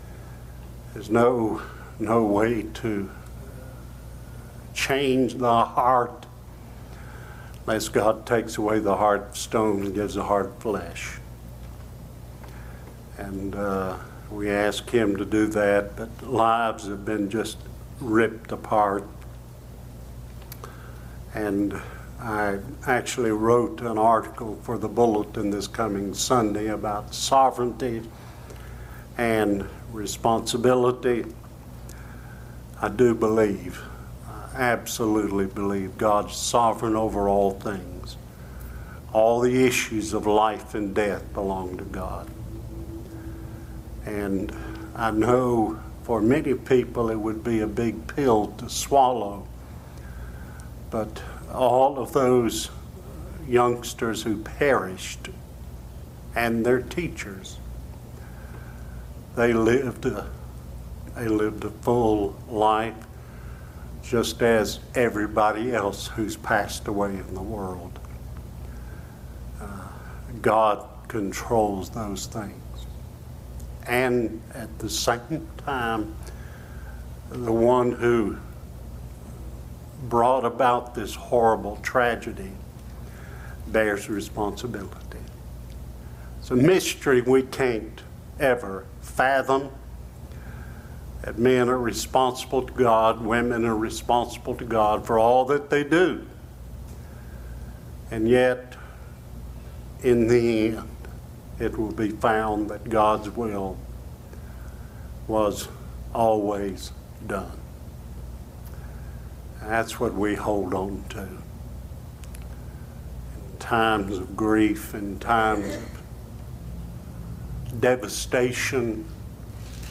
Joseph Tests His Brethren | SermonAudio Broadcaster is Live View the Live Stream Share this sermon Disabled by adblocker Copy URL Copied!